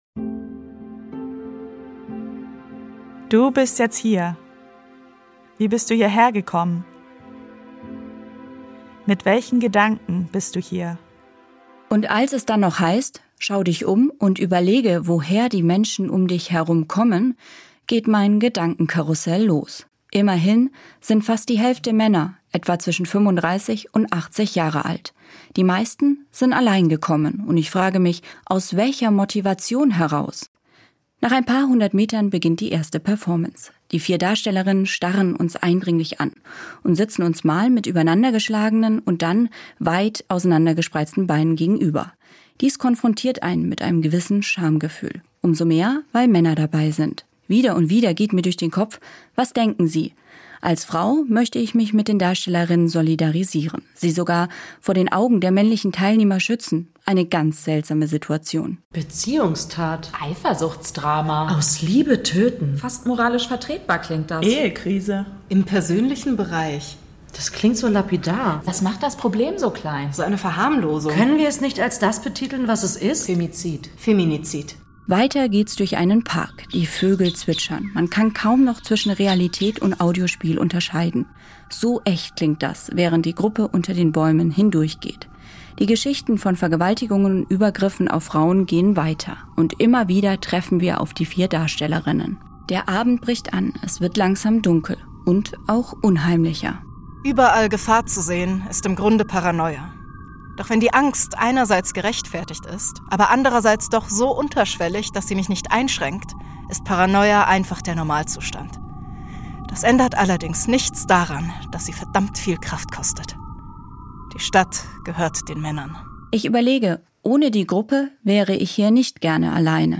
Kopfhörer auf den Köpfen, Schritte im Gleichklang, Stadtgeräusche im Ohr.
Die Besucherinnen und Besucher hören Stimmen, Klänge, Fakten und Erfahrungsberichte und treffen an mehreren Orten auf die Performerinnen.